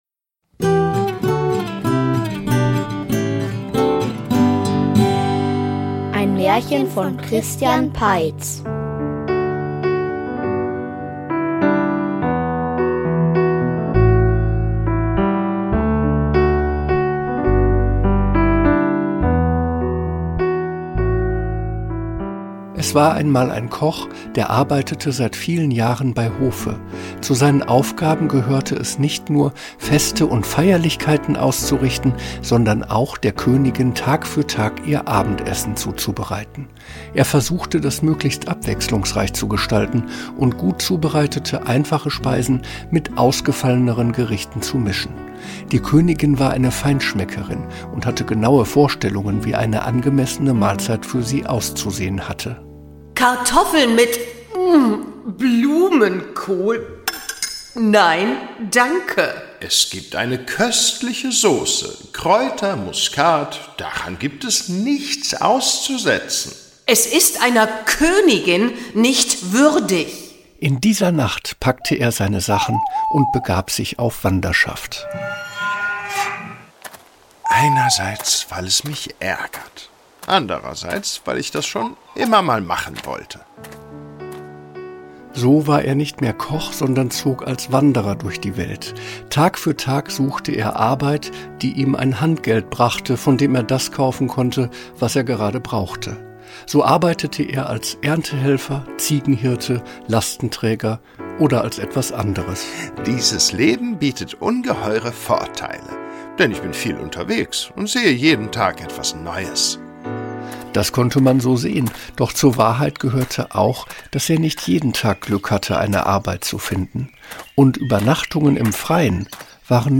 Um Mitternacht am andern Ort --- Märchenhörspiel #62 ~ Märchen-Hörspiele Podcast